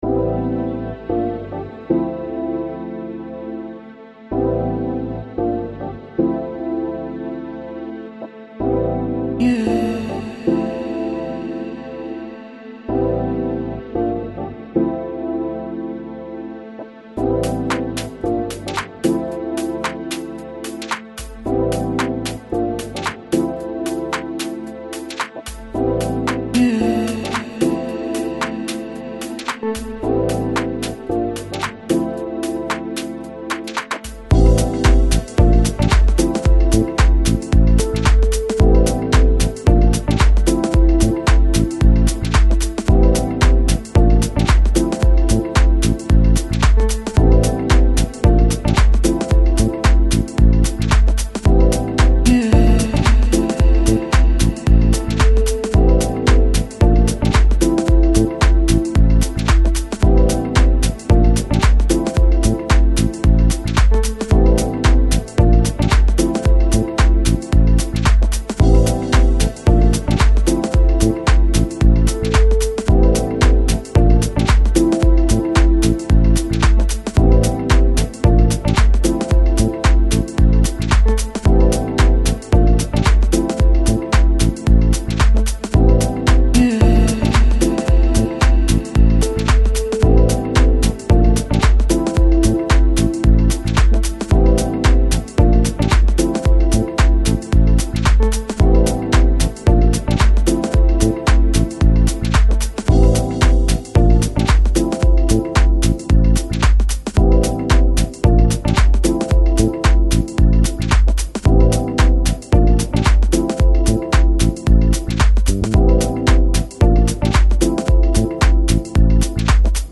Жанр: Electronic, Lounge, Downtempo, Lofi, Chill Out